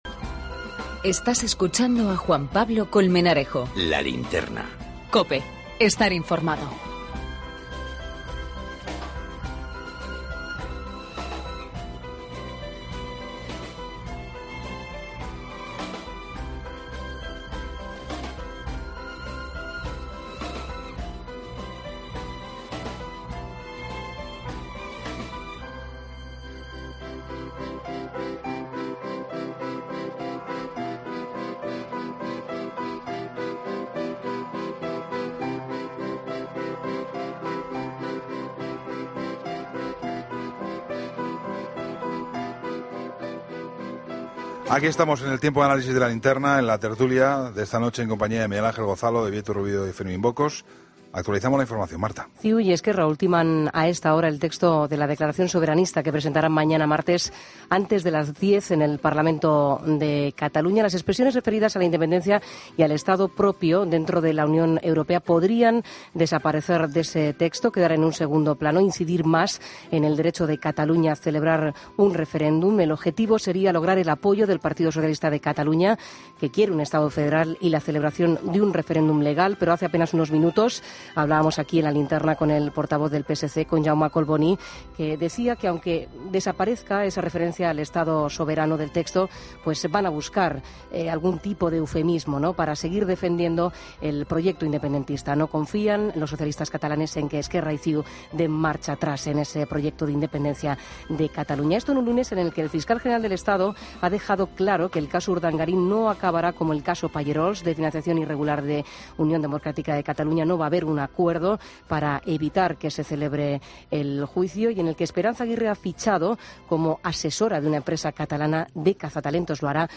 Tertulia I, lunes 14 de enero de 2013